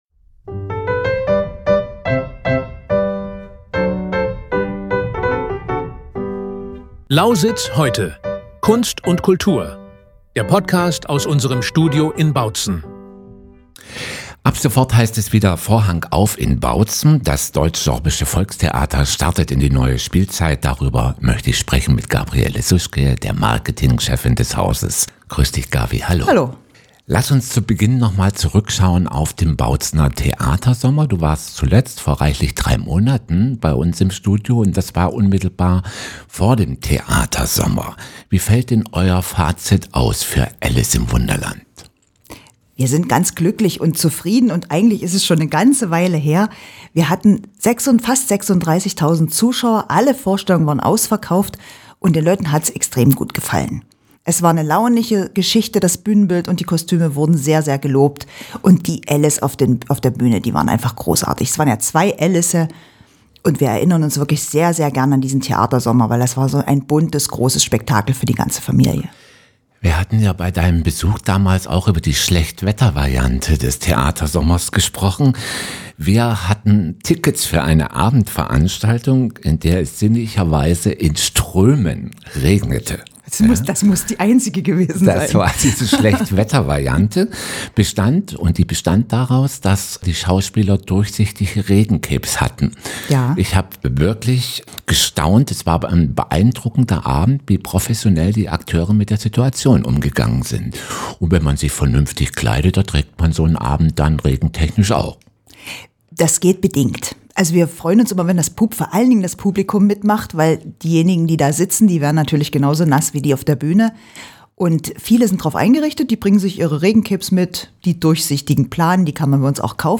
Ein Gespräch über Kunst, Kultur und die Faszination des Live-Erlebnisses Theater.